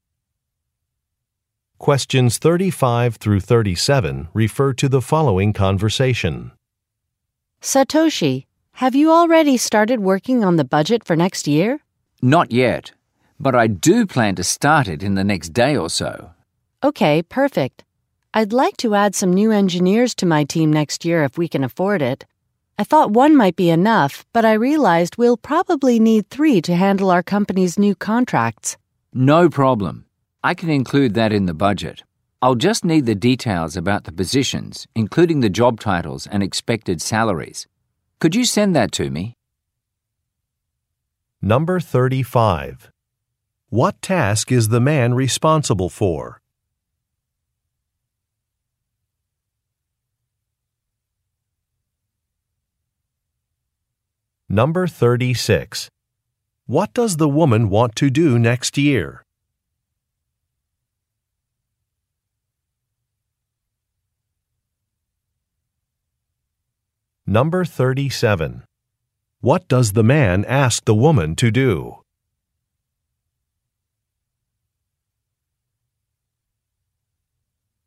Question 35 - 37 refer to following conversation: